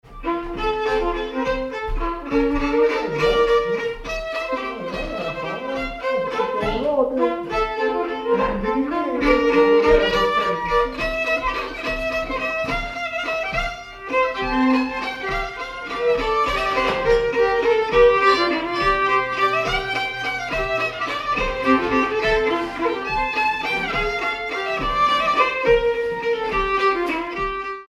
Polka
danse : polka
circonstance : bal, dancerie
Pièce musicale inédite